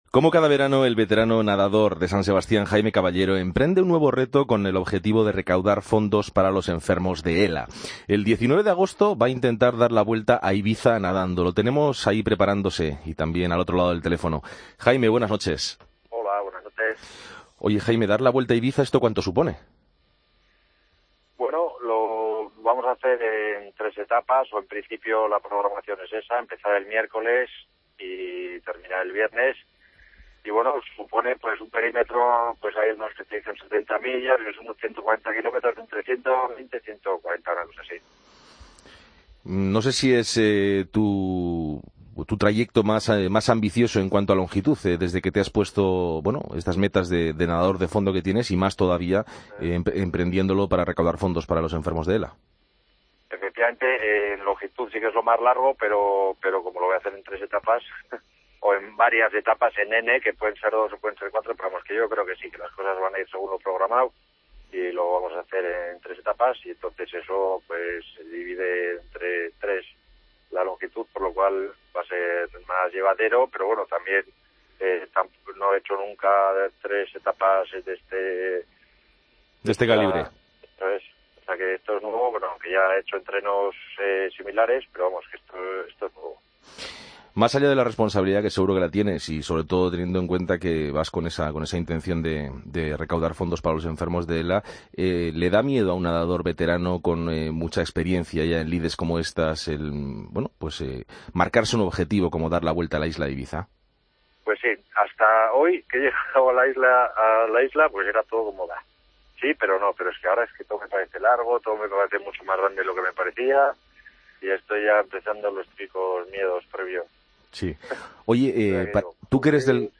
AUDIO: Nos lo cuenta el nadador.